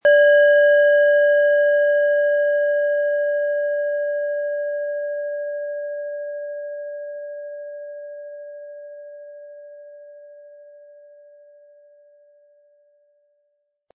Planetenschale® Verantwortlich sein können & konzentriert fühlen mit Saturn, Ø 10,5 cm, 260-320 gr. inkl. Klöppel
Mit viel Liebe und Sorgfalt in Handarbeit erstellte Klangschale.
Im Audio-Player - Jetzt reinhören hören Sie genau den Original-Klang der angebotenen Schale. Wir haben versucht den Ton so authentisch wie machbar aufzunehmen, damit Sie gut wahrnehmen können, wie die Klangschale klingen wird.
Kostenlos mitgeliefert wird ein passender Klöppel zur Schale, mit dem Sie die Töne der Schale gut zur Geltung bringen können.
MaterialBronze